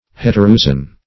Search Result for " heteroousian" : The Collaborative International Dictionary of English v.0.48: Heteroousian \Het`er*o*ou`si*an\, a. [Hetero- + Gr.
heteroousian.mp3